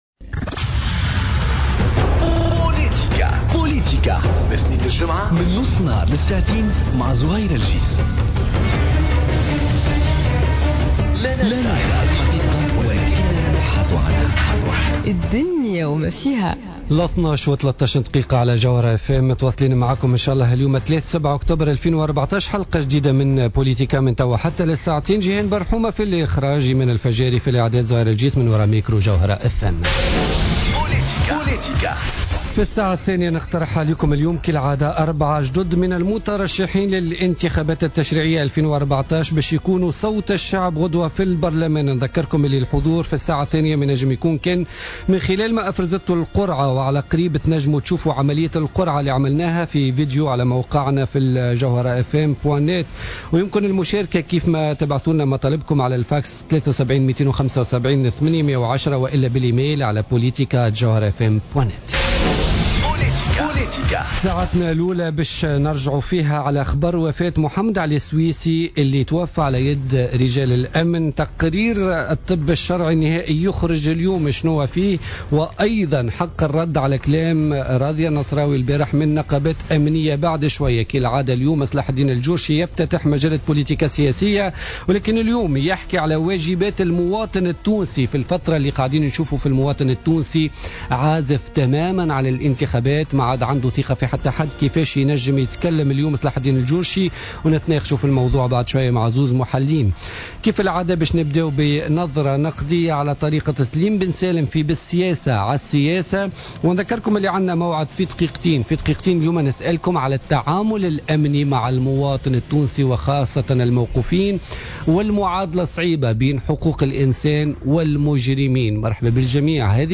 مداخلات ضيوف بوليتيكا